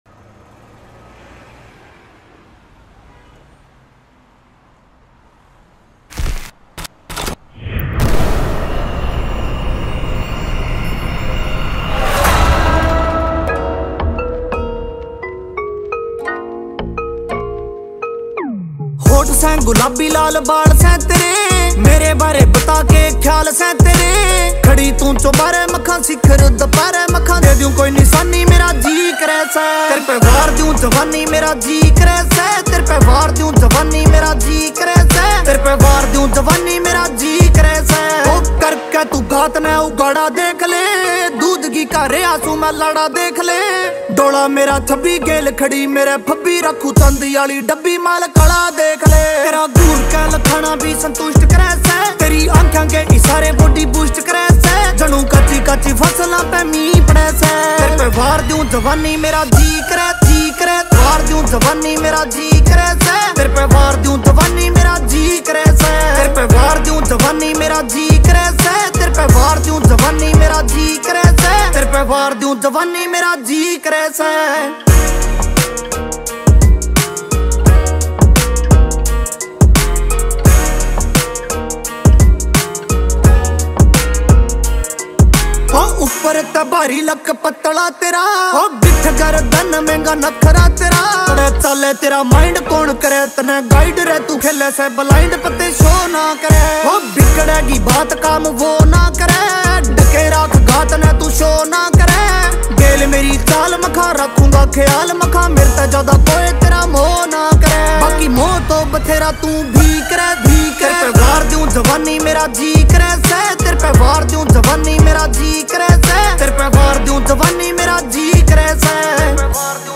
Category: New Haryanvi Mp3 Songs